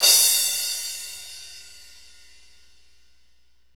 Index of /90_sSampleCDs/Northstar - Drumscapes Roland/CYM_Cymbals 2/CYM_R&B Cymbalsx
CYM R B CR01.wav